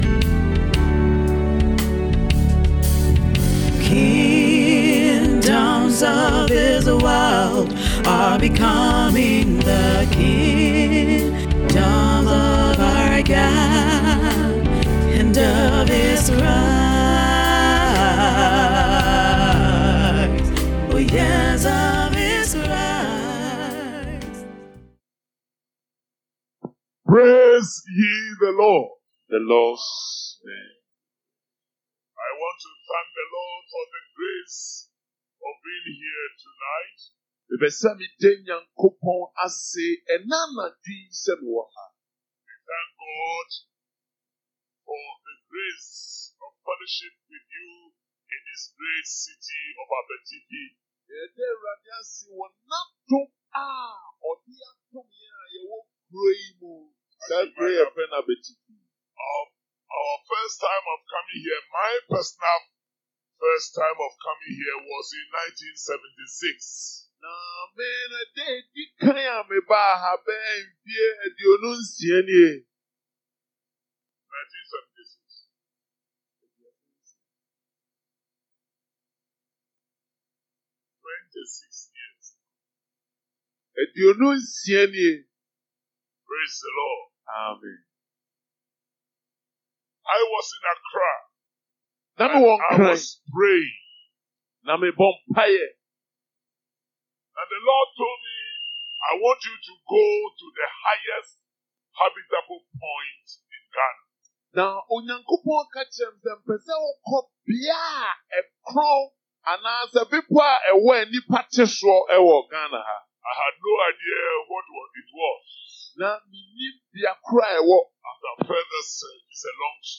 SERMON TITLE: REDEMPTION OF ABETIFI